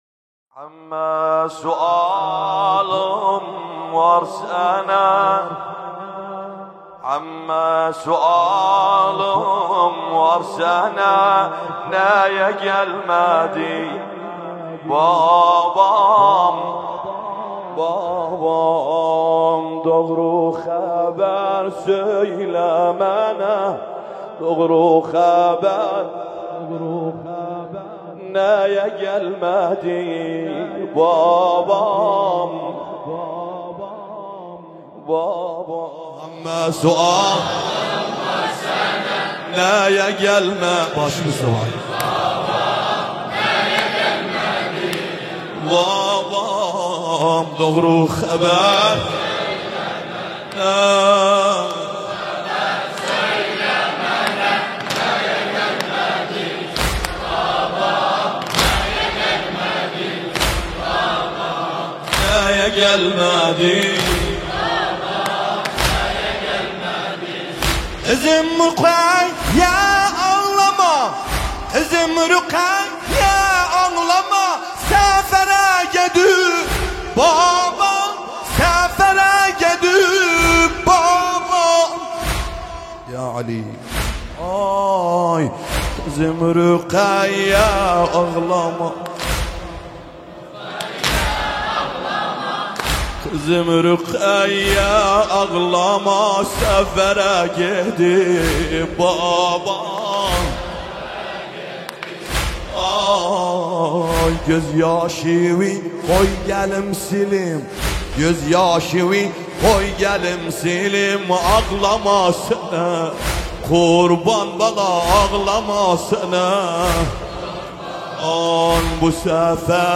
نوحه ترکی عراقی